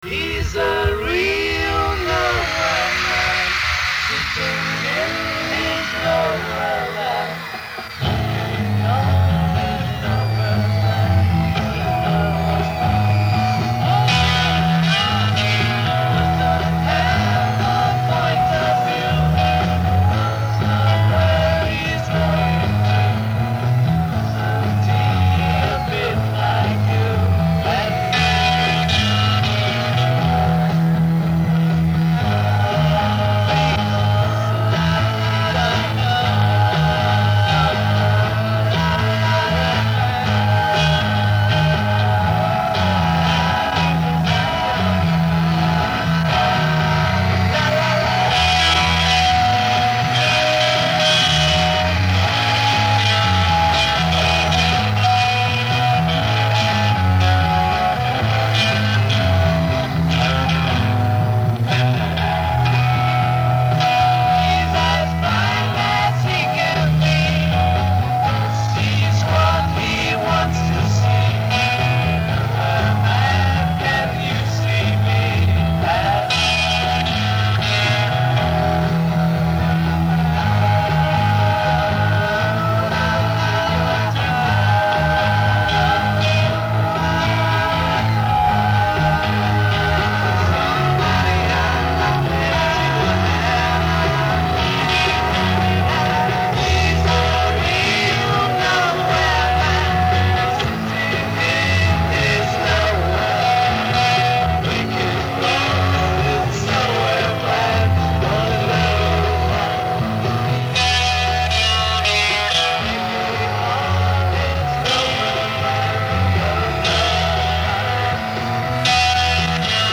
Candlestick Park